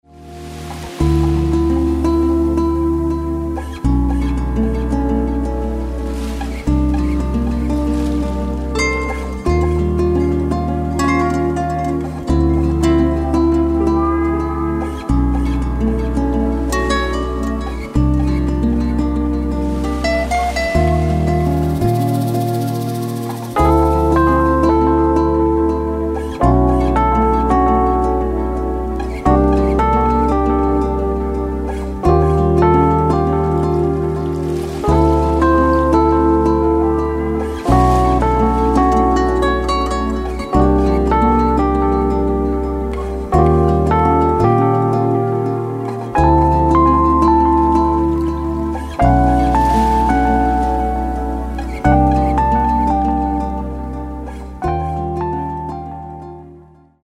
ディープで気怠いテナー・サックスが全編を漂う、セミ・インスト。